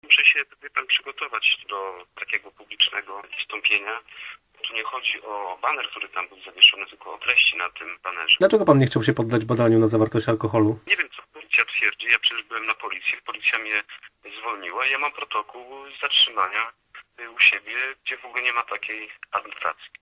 burmistrztabor_0102.mp3